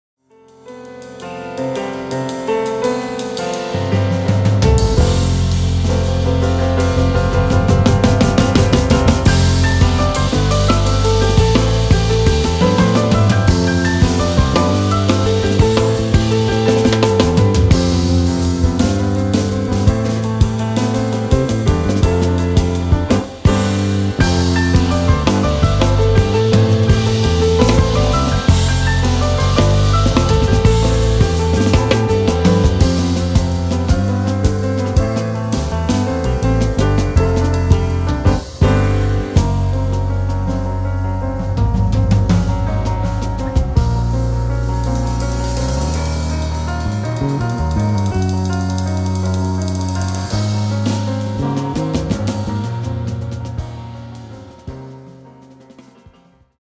痛快、爽快なジャズロックが展開される
piano
drums
bass
violin